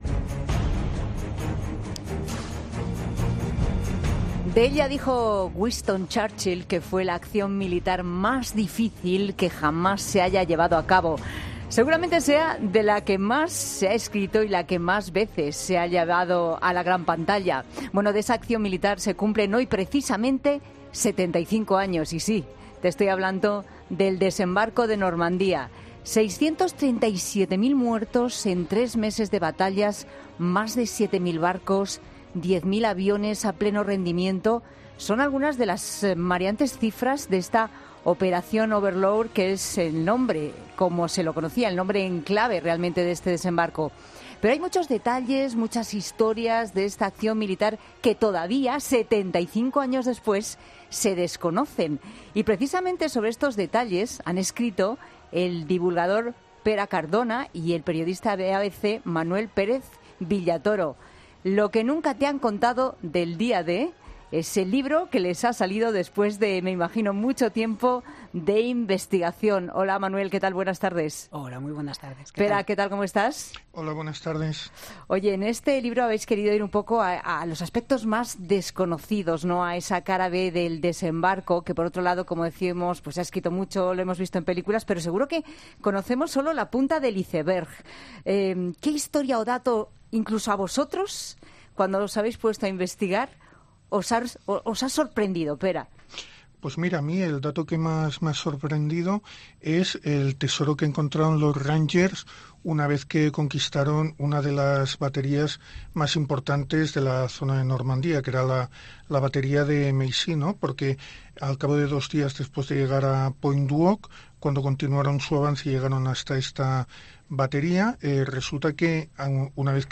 Cuando se cumplen 75 años del acontecimiento, hablamos en La Tarde con los autores del libro 'Lo que nunca te han contado del día D'